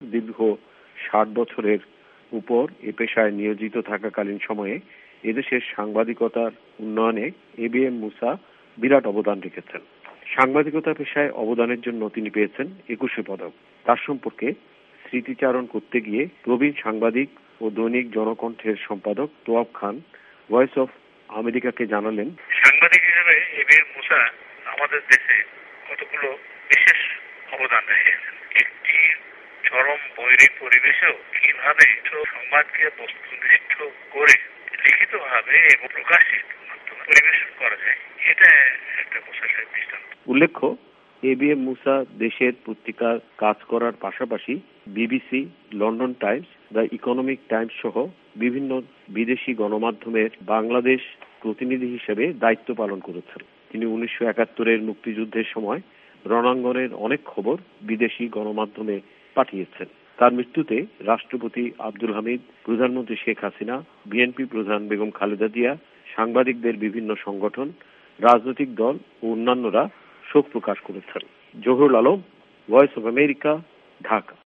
এ বি এম মুসার প্রয়াণ সম্পর্কে রিপোর্টটি শুনুন